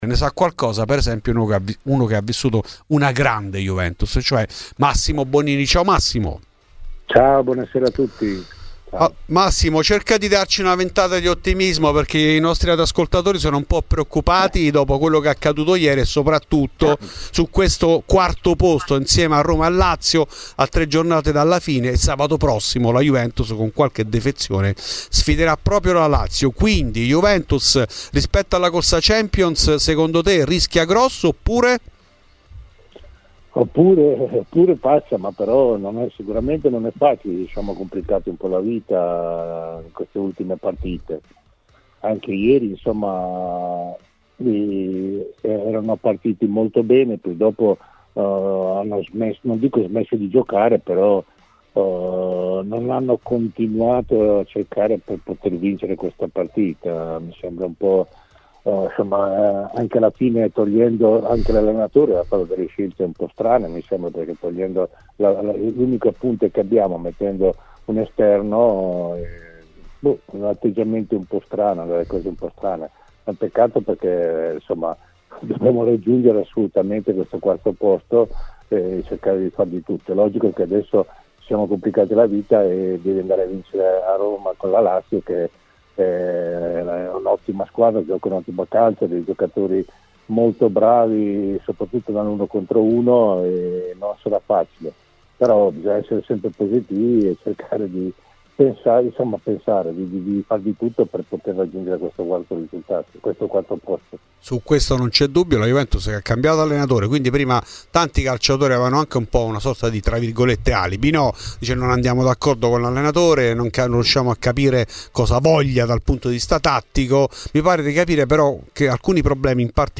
C'è bisogno di una stabilità di formazione e in generale chi è forte deve sempre essere nell'undici di partenza Ascolta il podcast per l'intervista integrale